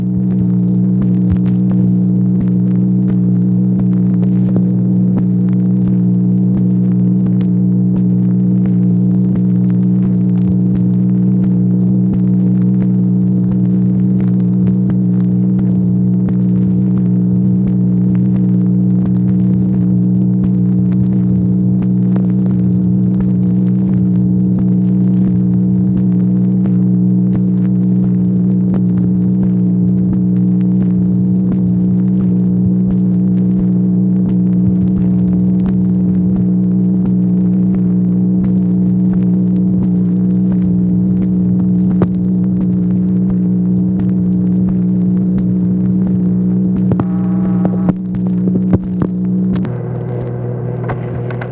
MACHINE NOISE, July 27, 1964
Secret White House Tapes | Lyndon B. Johnson Presidency